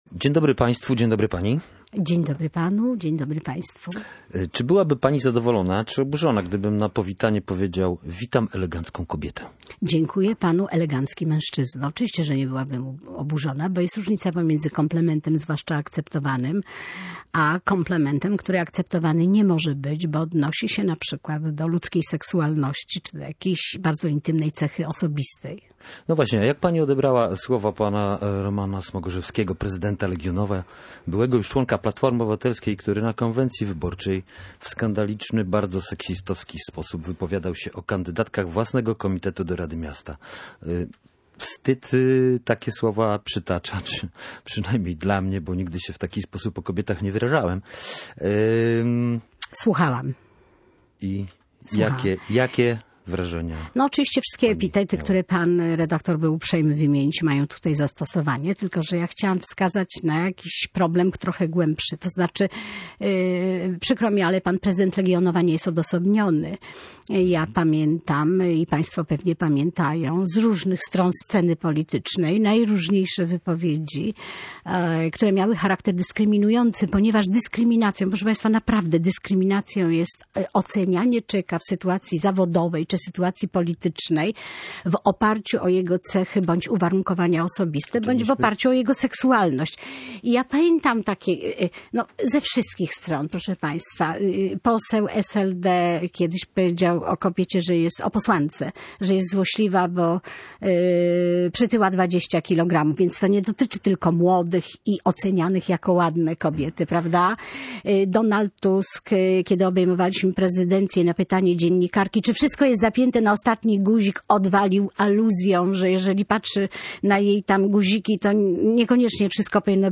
W Polsce jest kulturowe przyzwolenie na seksistowskie zachowania obrażające kobiety - mówiła w Radiu Gdańsk Jolanta Banach.